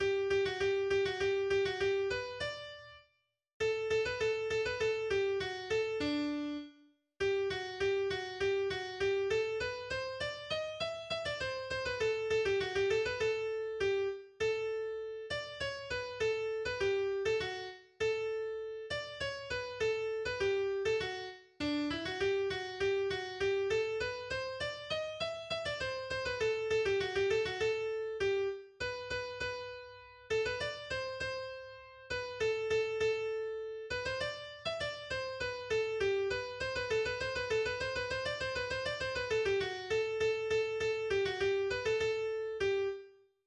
das auf eine ältere Volksweise gesungen wird.